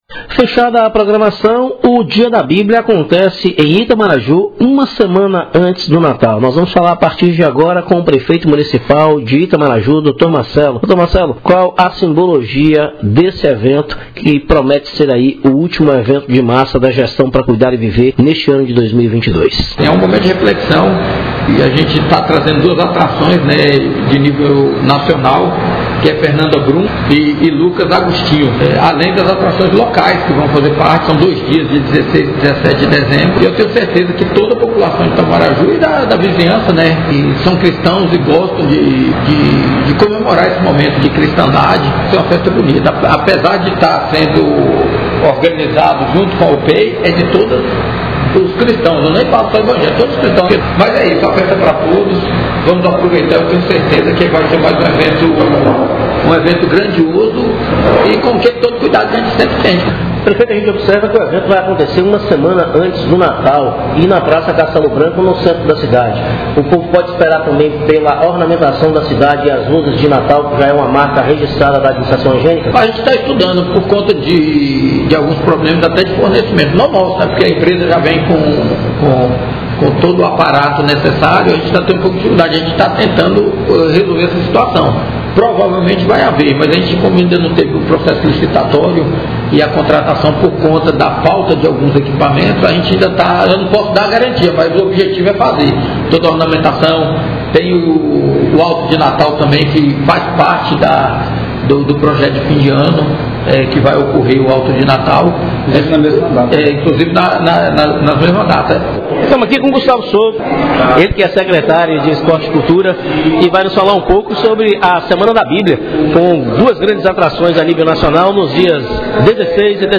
Nossa reportagem esteve acompanhando a solenidade de lançamento onde na oportunidade conversou com as autoridades presentes